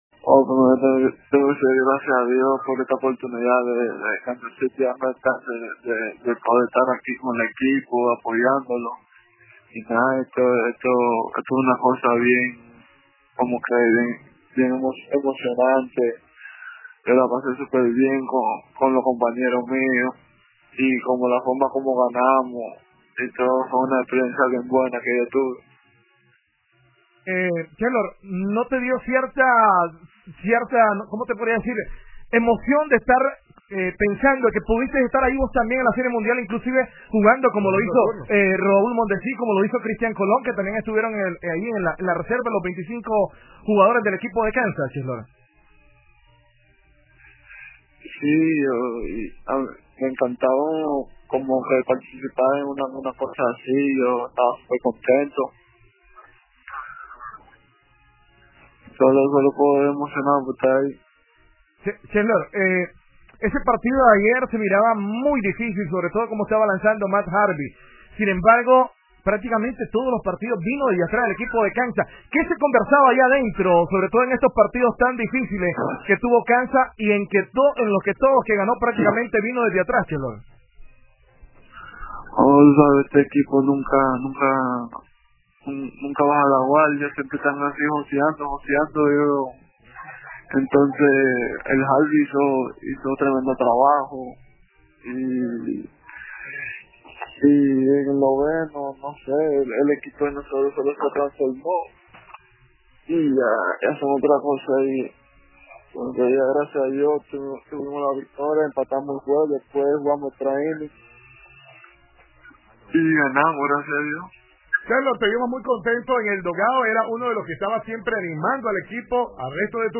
Entrevista exclusiva a Tu Nueva Radio YA
CHERLOR-CUTHBERT-ENTREVISTA.mp3